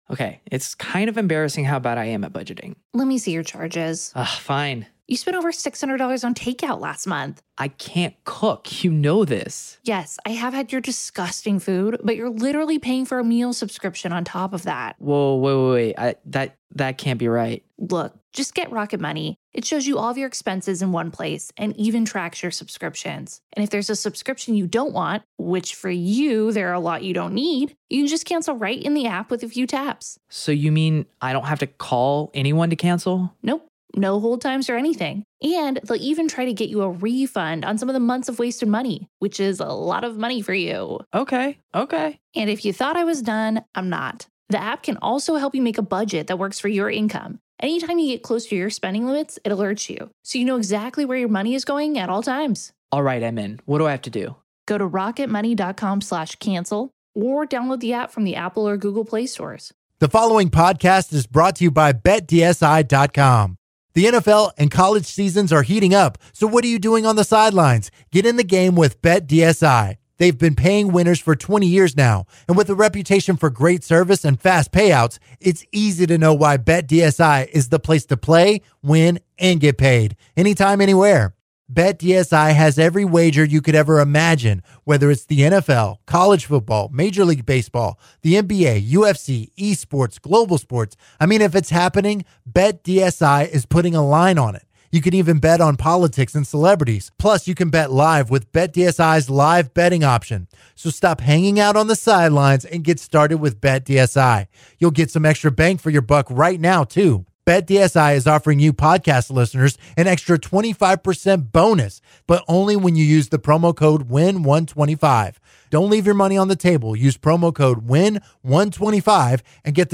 The guys are joined by TAMU Football head coach Jimbo Fisher via phone to discuss their latest win against UAB, running back Trayveon Williams’ stellar year, the senior players’ final game, and their upcoming game against LSU. The guys help a listener solve a problem with his mom. The guys also discuss the Hornets’ Kemba Walker and NBA salaries.